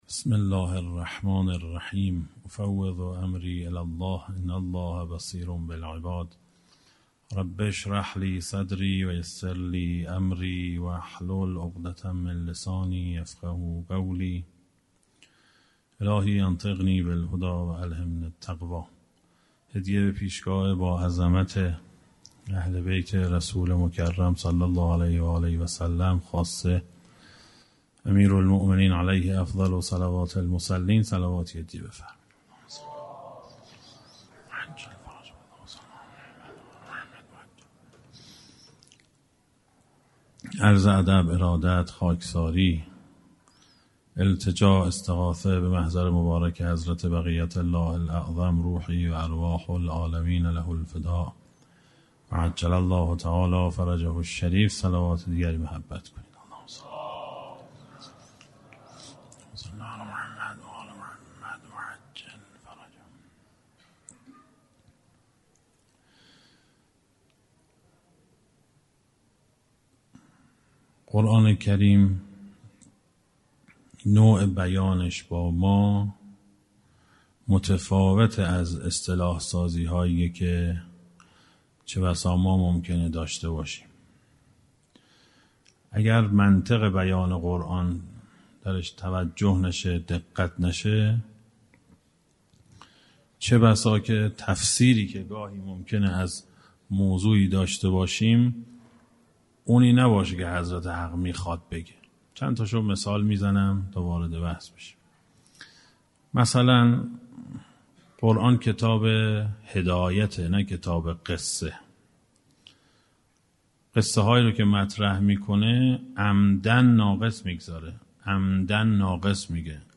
اشتراک گذاری دسته: درنگی در منطق قرآن کریم , سایر مناسبت ها , سخنرانی ها قبلی قبلی سیر تکوّن عقاید شیعه – جلسه سی و دوم بعدی سیر تکوّن عقاید شیعه – جلسه سی و سوم بعدی